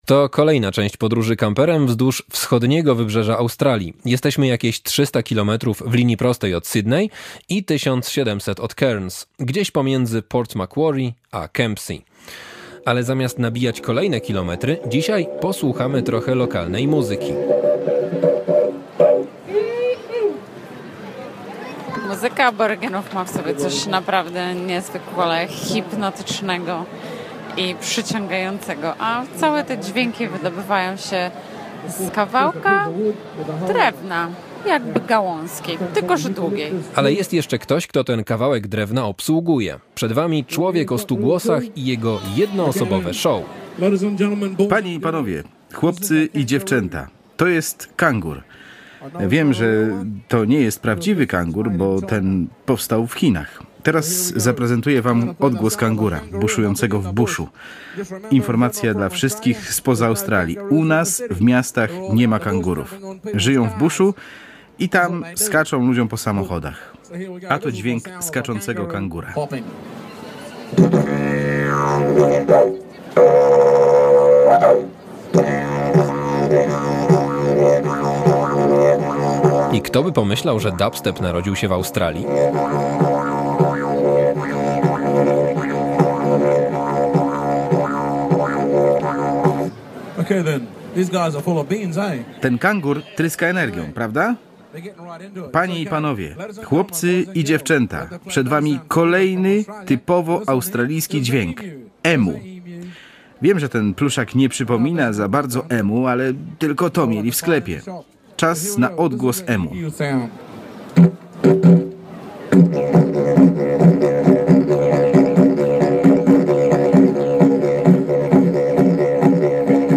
Jeden człowiek, jeden instrument, tysiąc głosów | Marzyciele z końców świata - Radio Łódź
Dzisiaj słuchamy prawdziwego show w wykonaniu ulicznego artysty. Ten utalentowany Australijczyk naśladuje odgłosy zwierząt, grając na tradycyjnym instrumencie.
Nazwa Plik Autor Dźwięki zwierząt. audio (m4a) audio (oga) Warto przeczytać Kolejny transfer Widzewa!